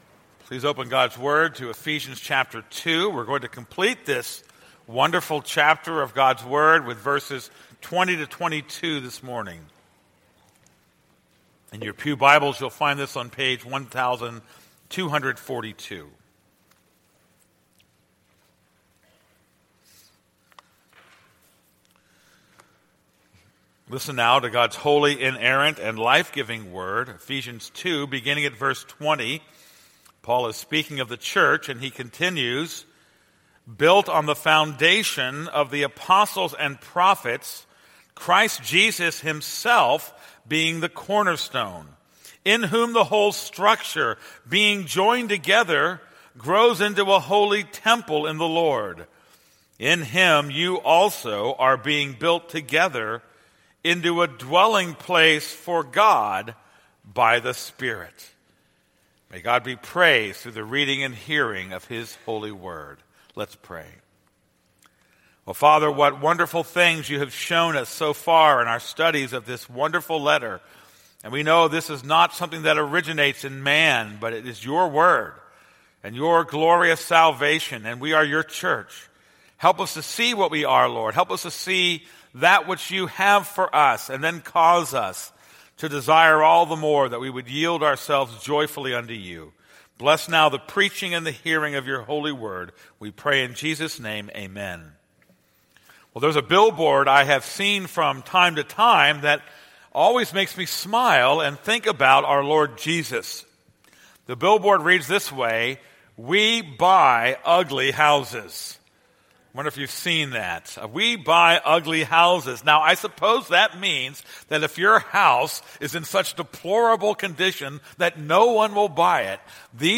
This is a sermon on Ephesians 2:20-22.